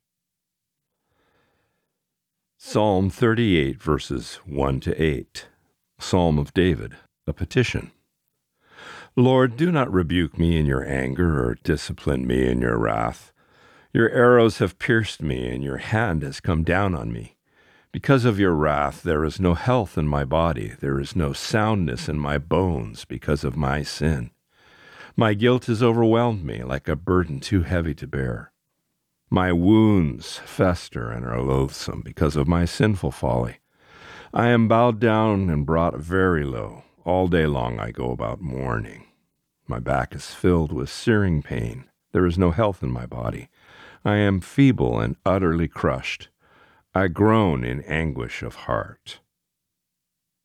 Reading: Psalm 38:1-8